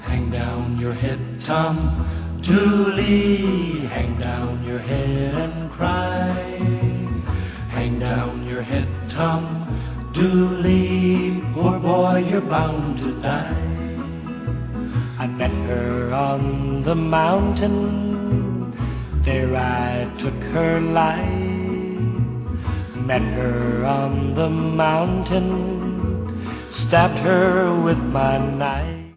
Műfajfolk